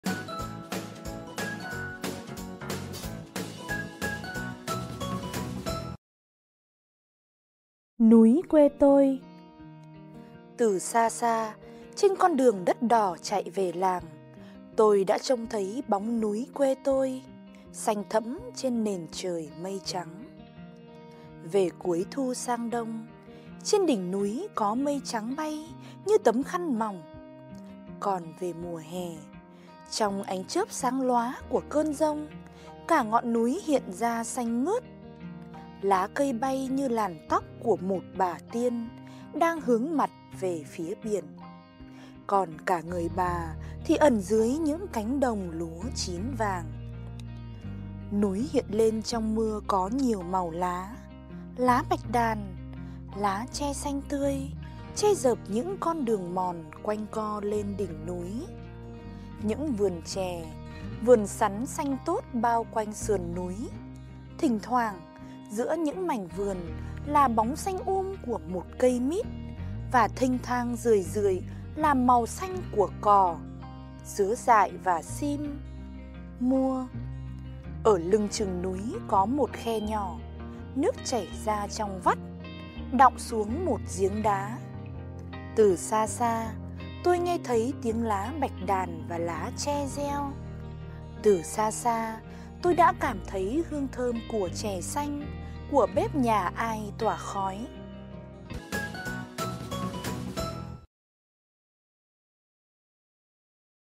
Sách nói | Núi quê tôi